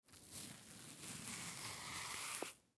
Звук очищения банана от кожуры